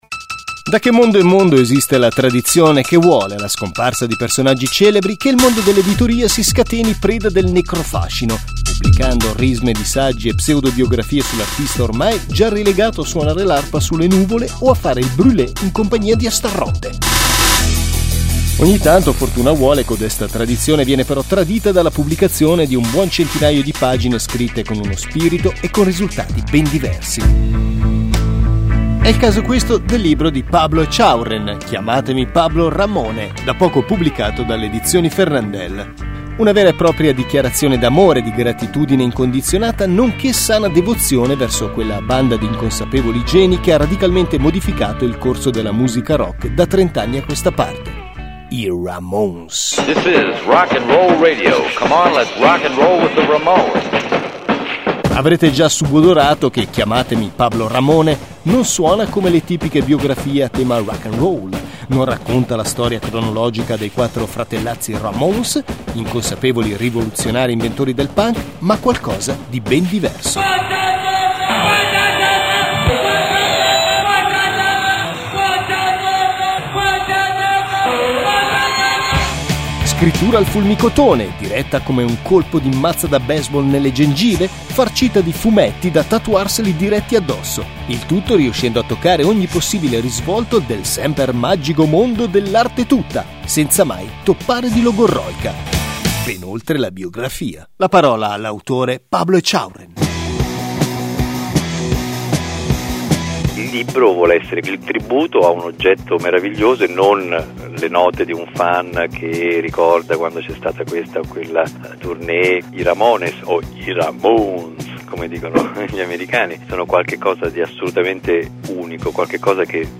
Radio e Tv Intervista a Radio 3 Fahrenheit (24 maggio 2006) Pablo Echaurren intervistato a Radio Svizzera (25 maggio 2006) Radio Emilia Romagna dedica un servizio al libro e alla musica dei Ramones (25 ottobre 2018)